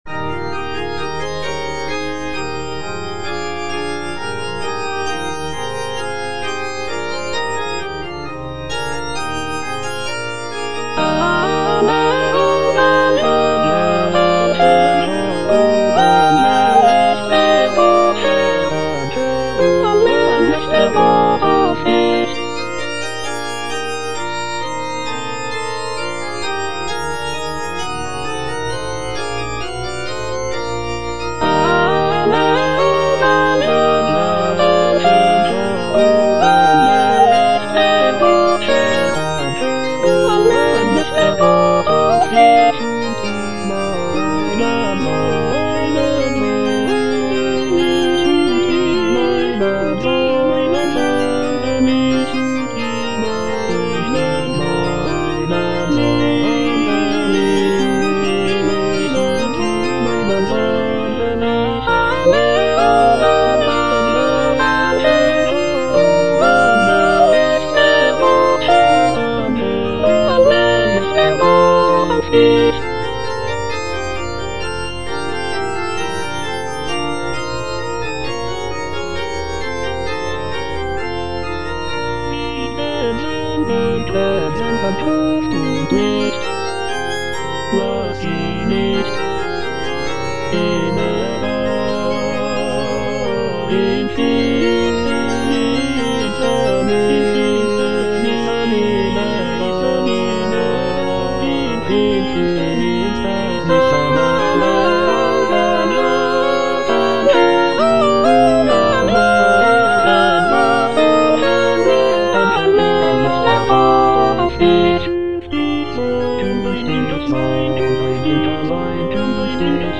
Choralplayer playing Cantata
The work features a festive opening chorus, expressive arias, and a lively final chorale.